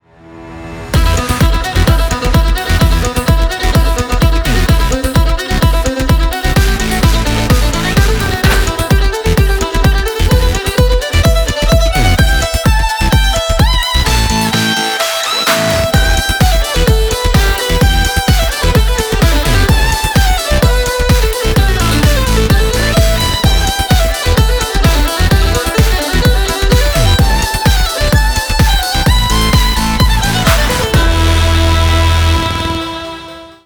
• Качество: 320, Stereo
скрипка
Талантливая скрипачка из Лондона